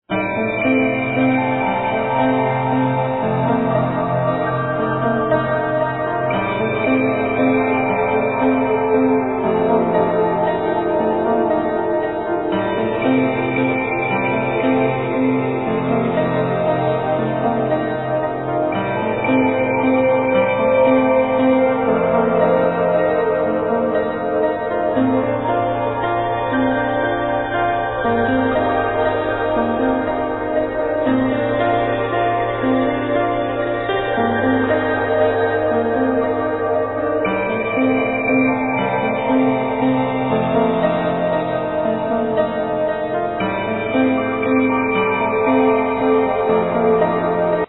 Synthesizer, Voice